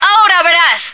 flak_m/sounds/female1/est/F1yousuck.ogg at ac4c53b3efc011c6eda803d9c1f26cd622afffce
F1yousuck.ogg